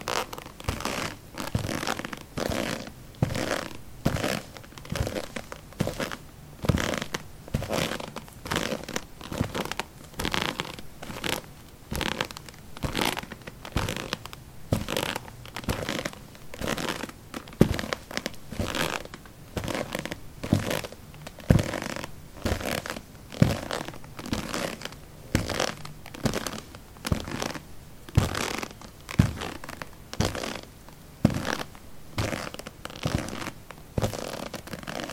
Footsteps pavement » paving 16a trekkingshoes walk
描述：Walking on pavement tiles: trekking shoes. Recorded with a ZOOM H2 in a basement of a house: a wooden container filled with earth onto which three larger paving slabs were placed.
标签： footsteps steps step walk footstep walking
声道立体声